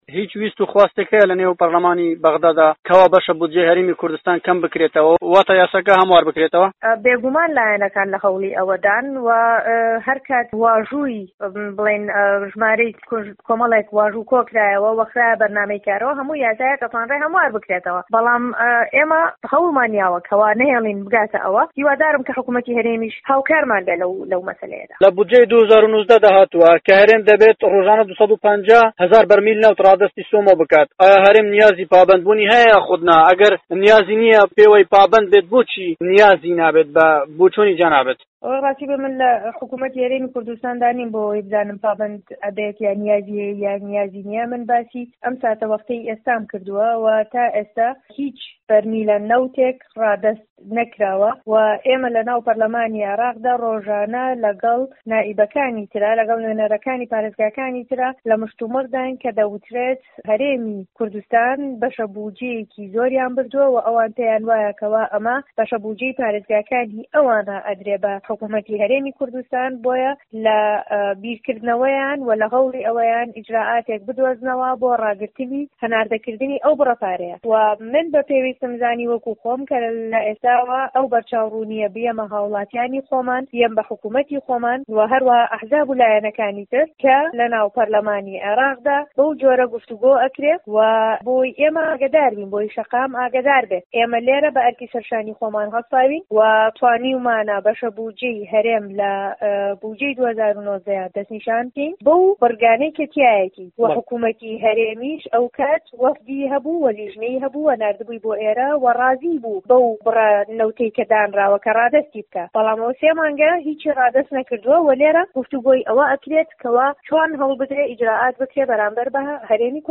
وتووێژ لەگەڵ بڵێسە جەبار